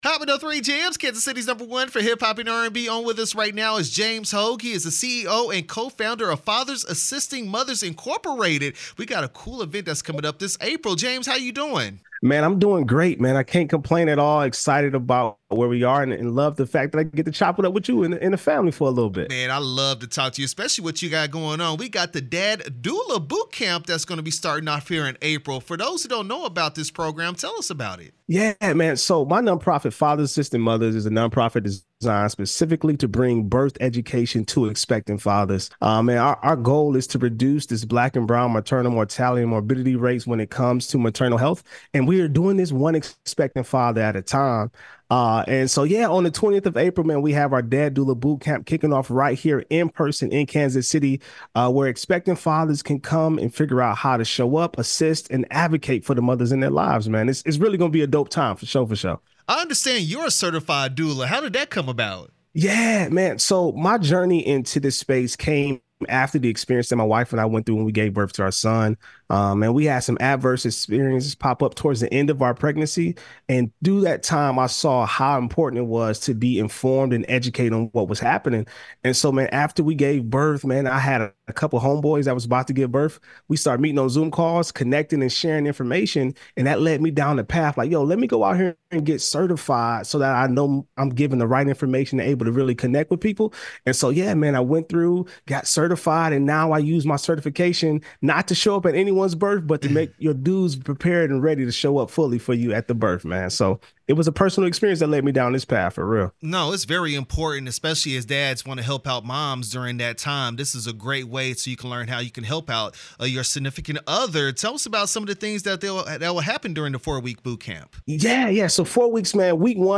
Fathers Assisting Mothers Inc-Dad Doula Bootcamp interview 4/1/24